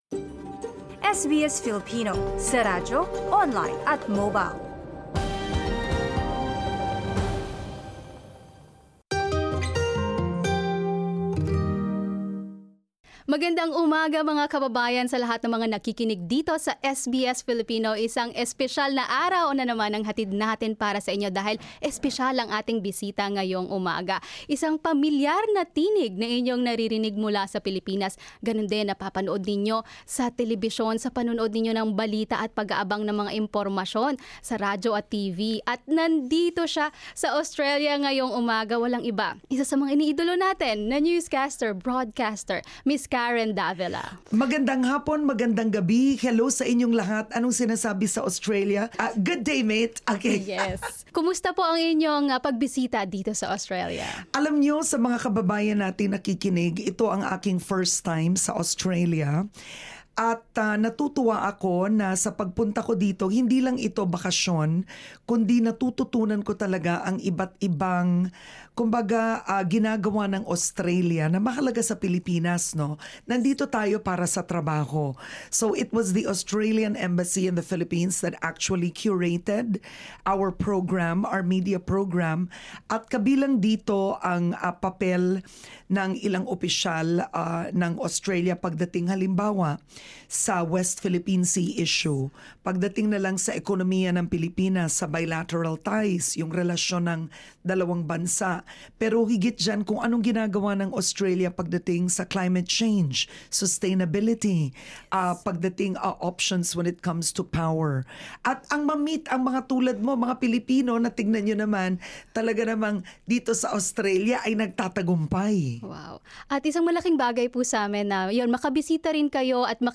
In an interview with SBS Filipino, renowned Filipino journalist Karen Davila reflects on her career in broadcasting and more recently, navigating the path of a content creator.
karen-davila-interview.mp3